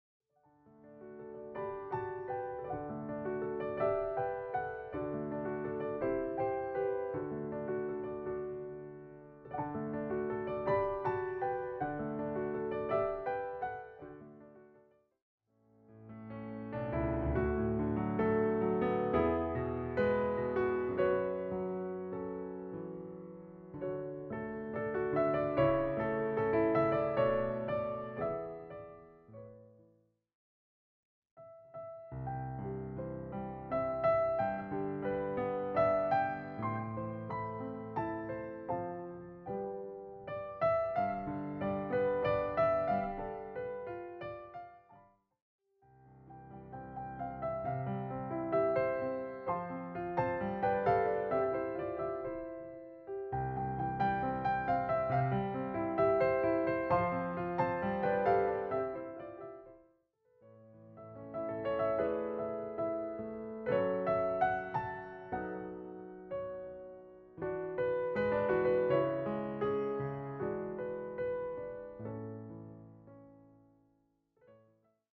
all given a solo piano treatment.
solo piano